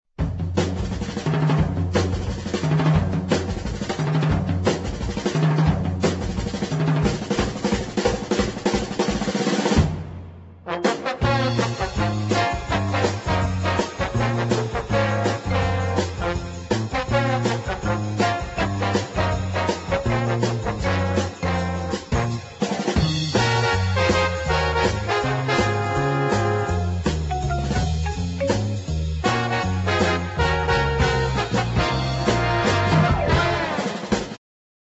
1968 exciting medium instr.